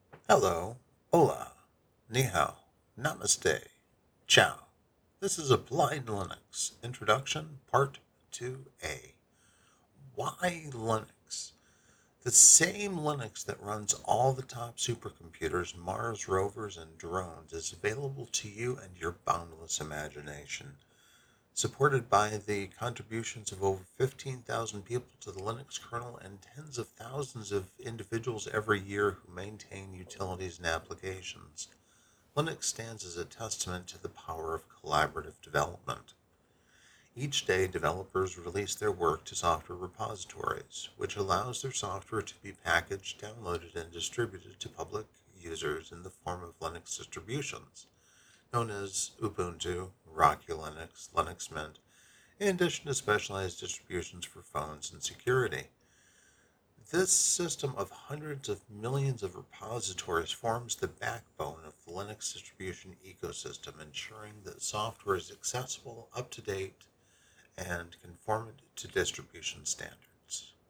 'Audiobook